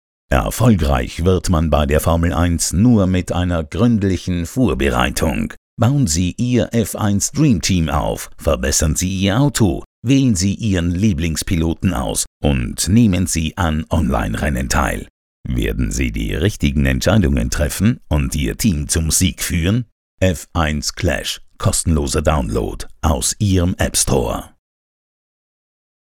Freundliche tiefe Männerstimme, akzentfrei und hochdeutsch.
Video Games
Werbung Spiel